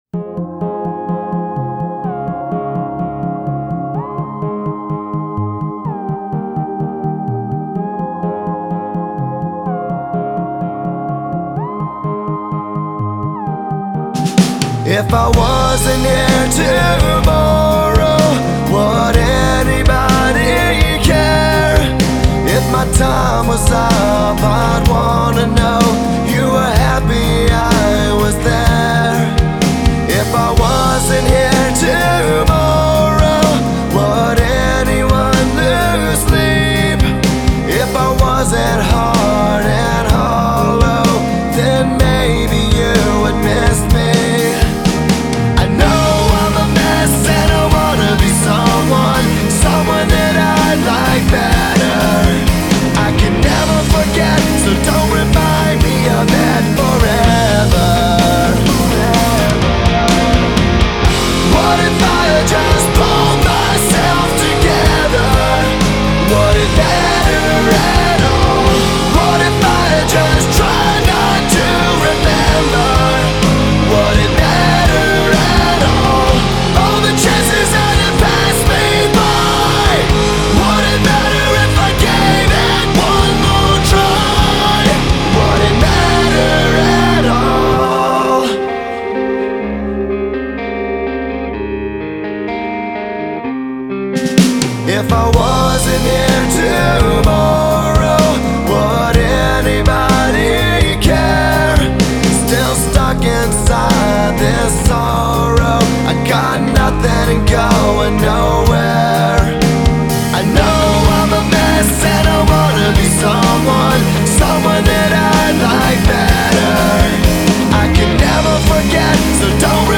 Hard Rock
rock music